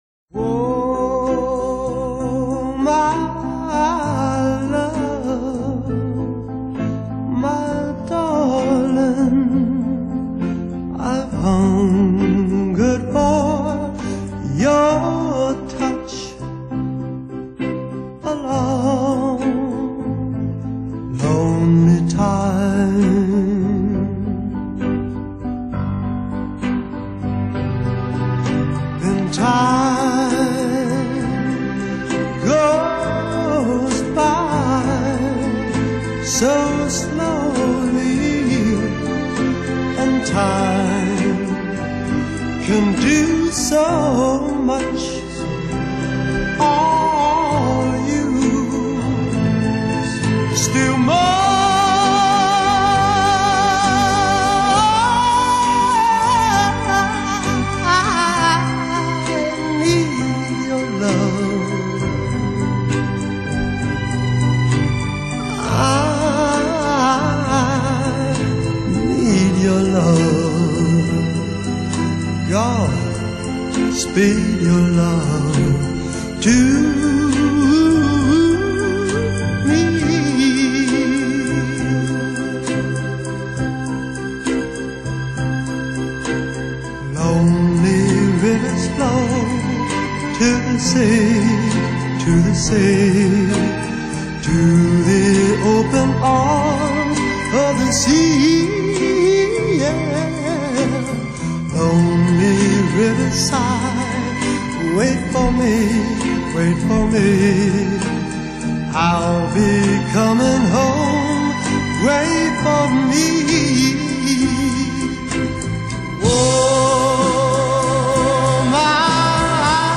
Soul, Pop | MP3 CBR 320 kbps | 57:26 min | 130 MB/115